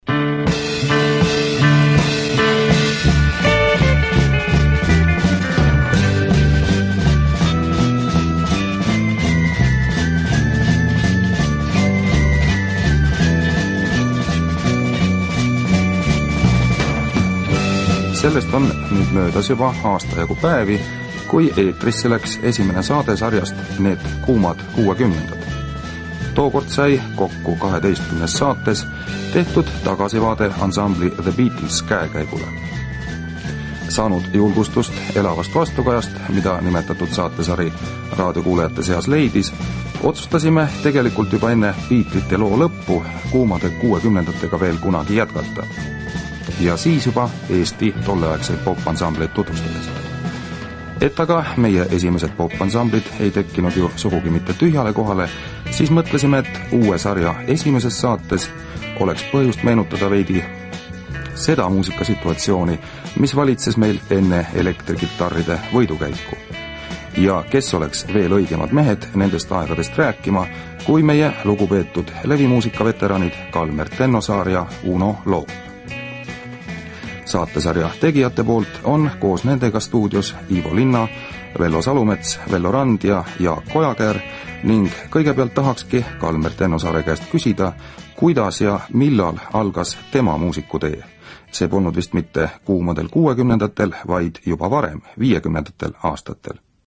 звучащая целиком если бы не голос репортёра